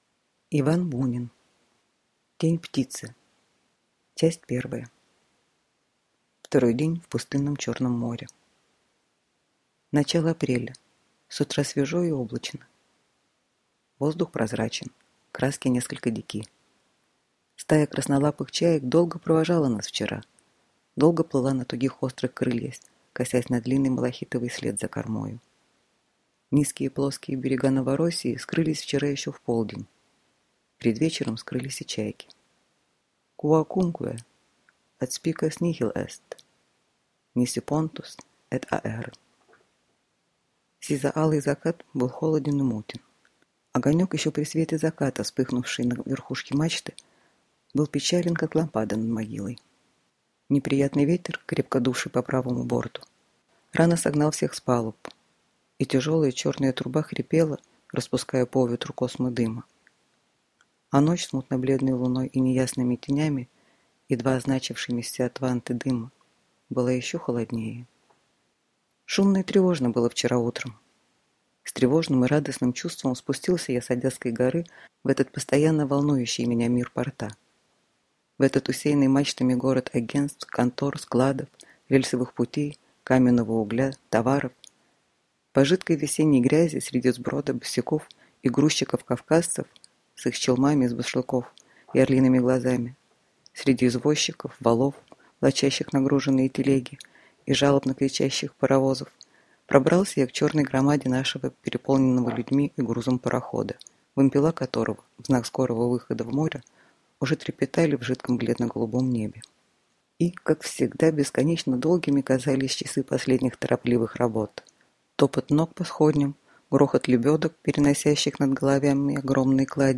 Аудиокнига Тень птицы | Библиотека аудиокниг